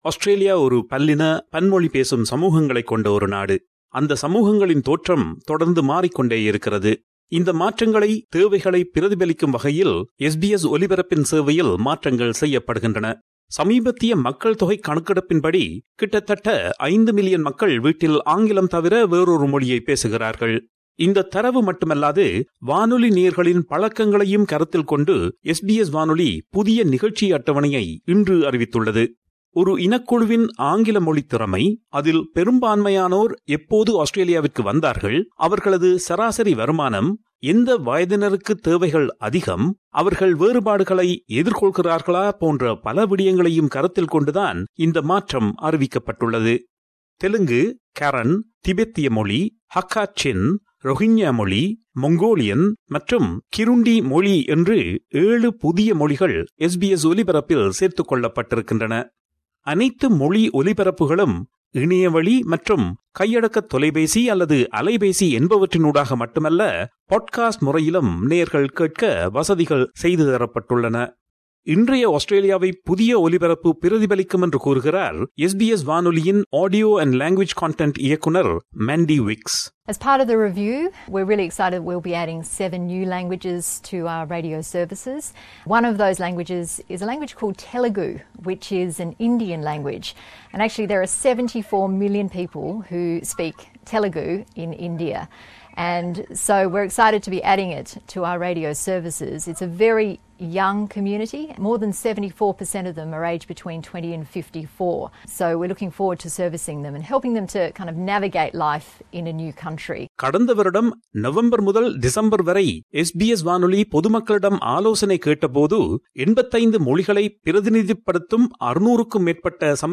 SBS radio